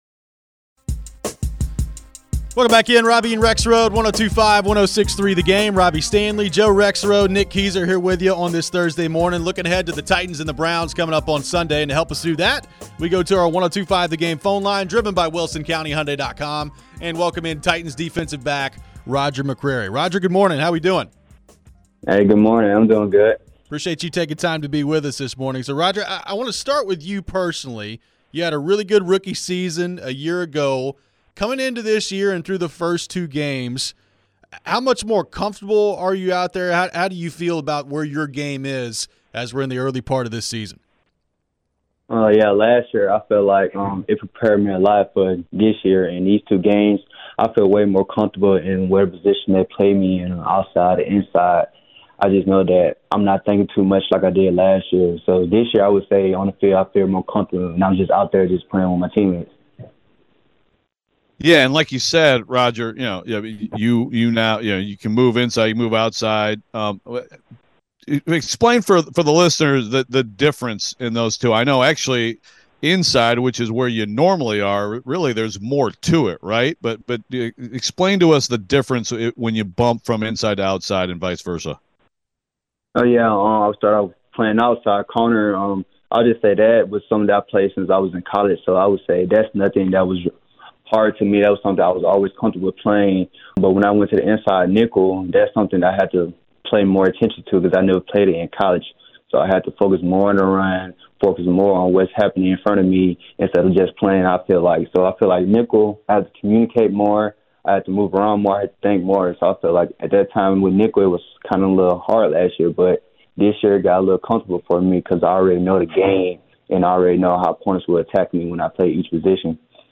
Roger McCreary Interview (9-21-23)